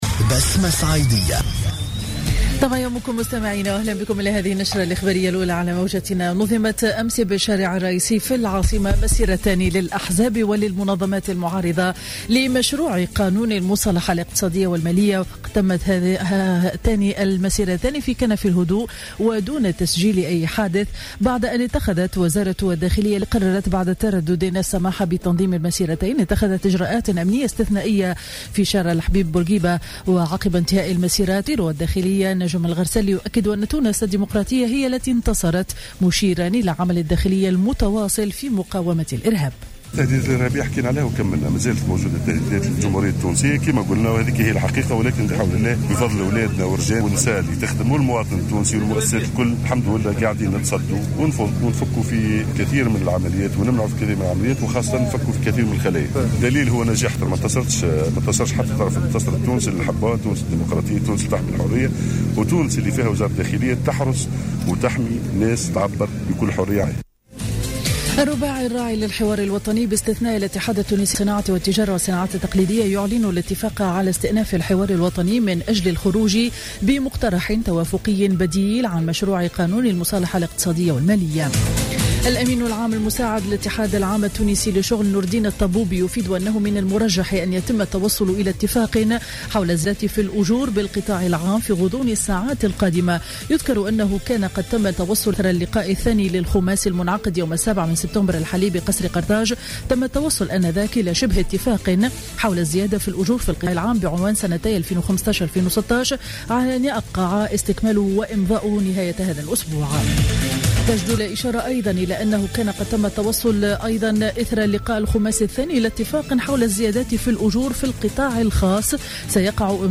نشرة أخبارالسابعة صباحا ليوم الأحد 13 سبتمبر 2015